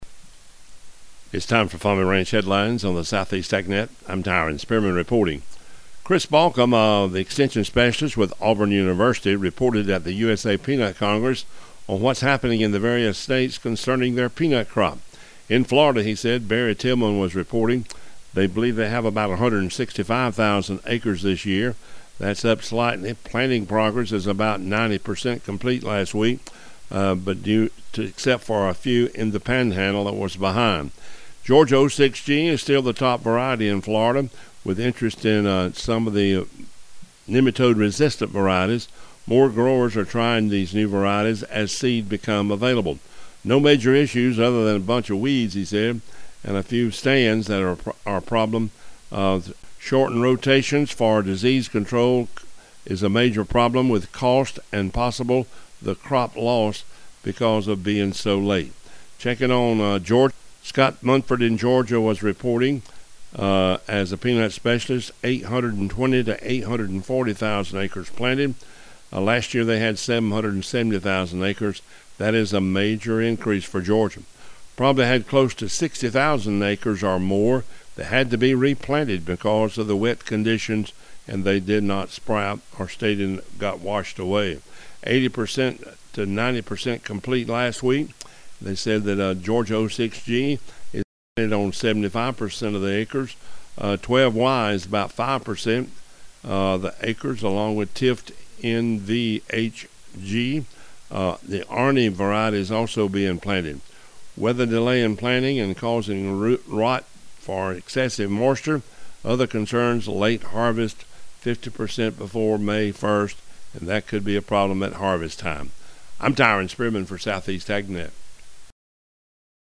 07-01-Pnt-Report.mp3